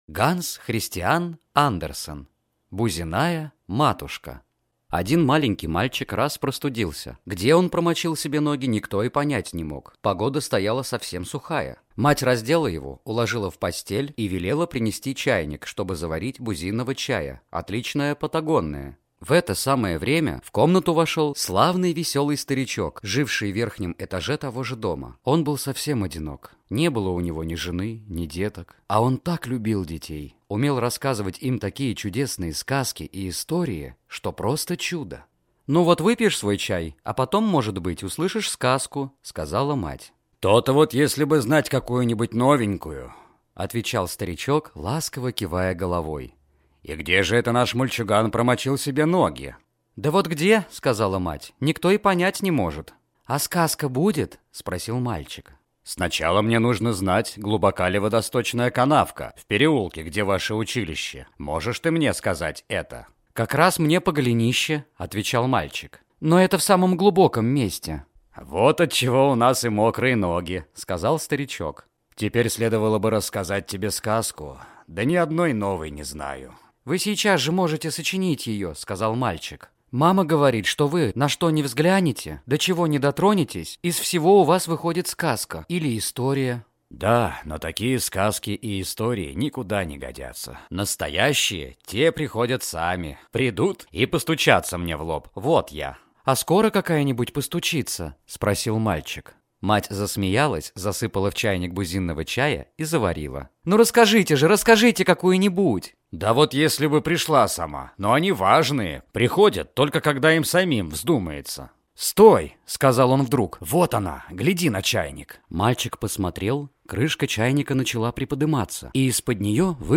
Аудиокнига Бузинная матушка | Библиотека аудиокниг